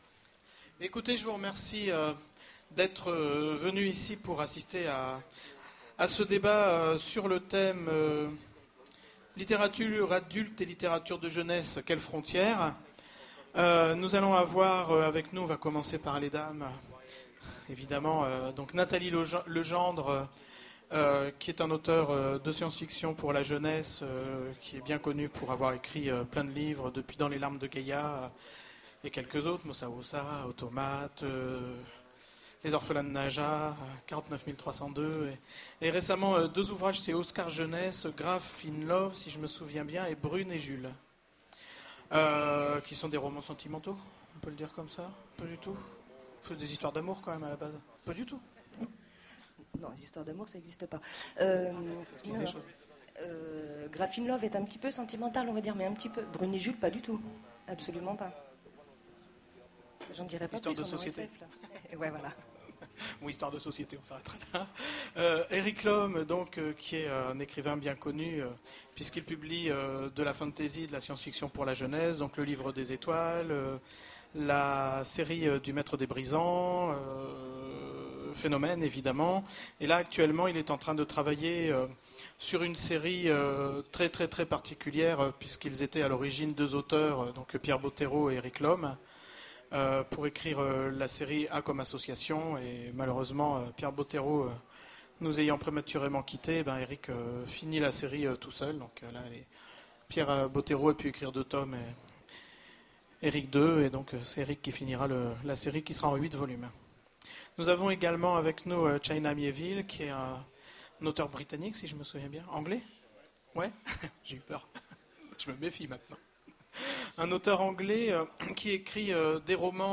Utopiales 2010 : conférence Littérature adulte et littérature jeunesse, quelles frontières ?